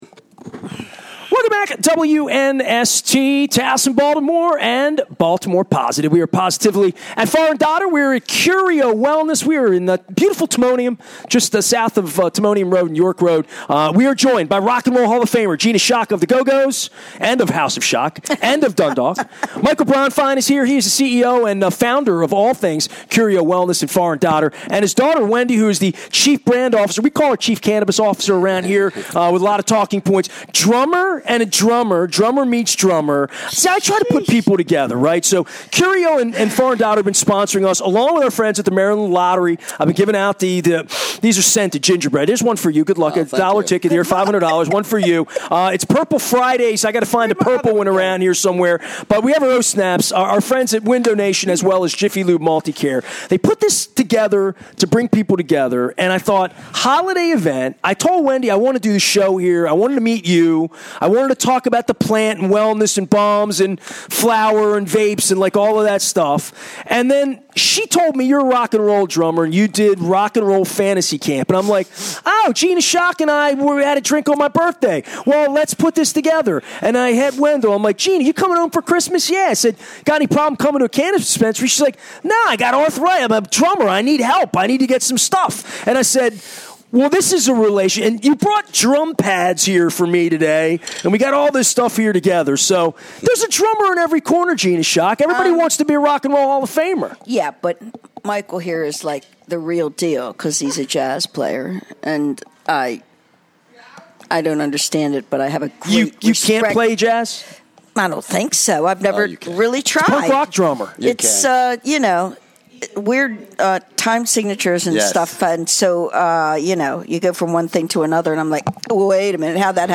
The Maryland Crab Cake Tour brought a spirited holiday edition of the show to Far & Dotter in Timonium